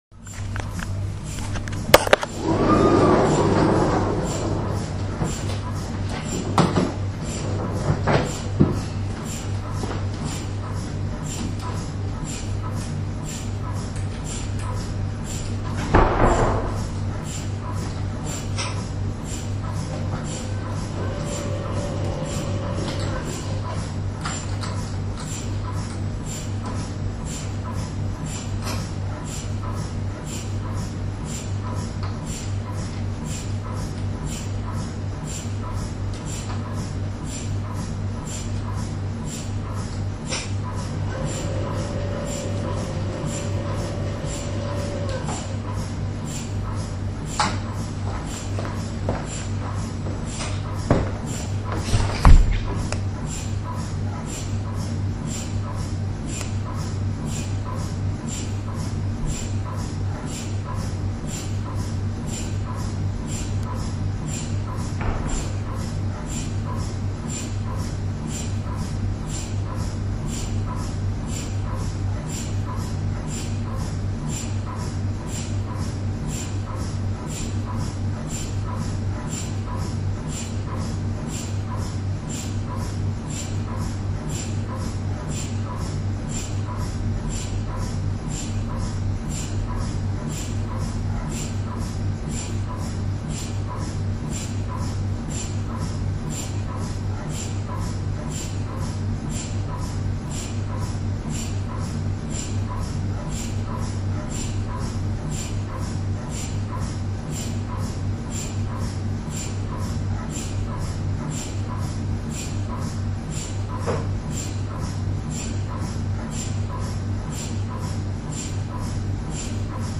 downloadable recording of an MRI scanner (opens in a new tab)
While the sounds may not match exactly, it can help familiarise your child with what to expect.
mri.mp3